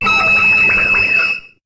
Cri de Neitram dans Pokémon HOME.